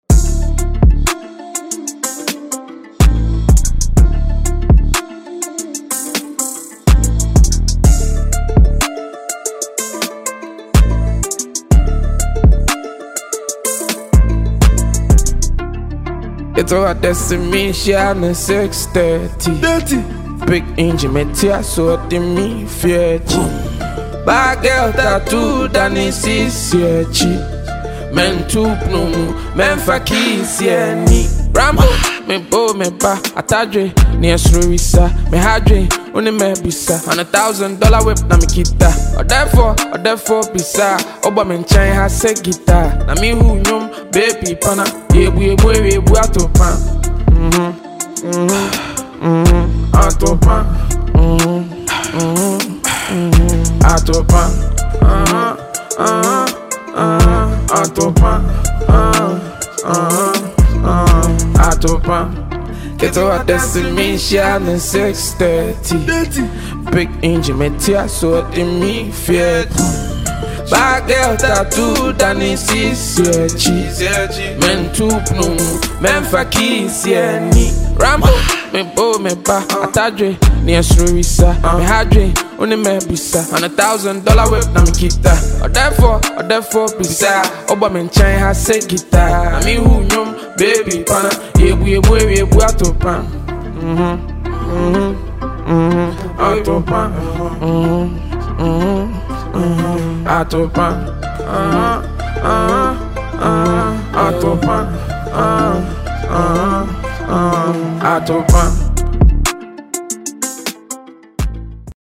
a Ghanaian rapper
" a solo track for the mandem.